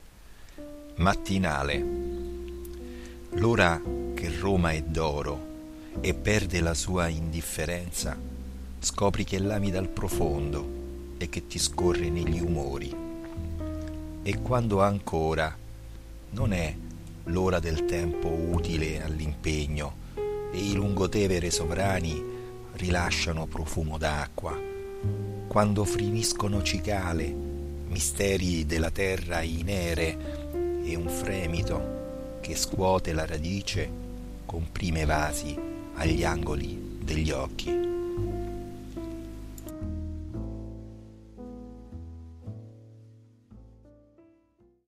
mattinale ok music.mp3